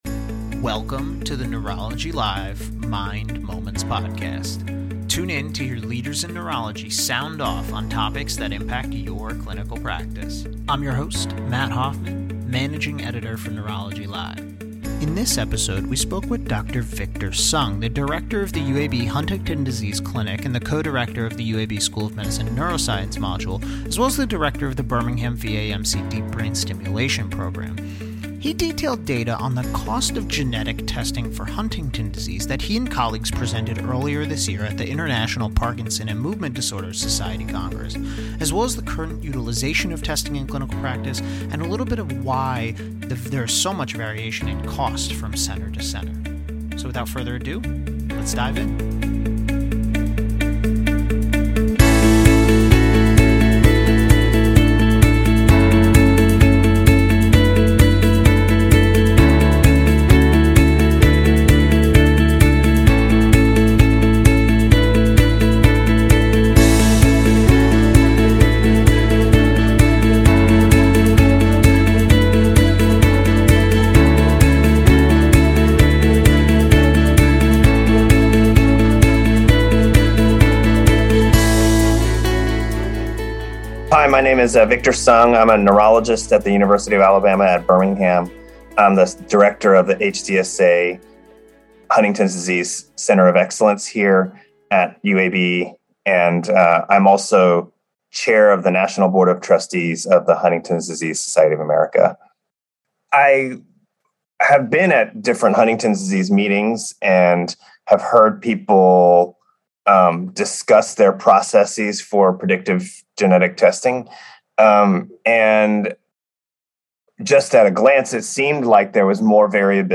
Tune in to hear leaders in neurology sound off on topics that impact your clinical practice.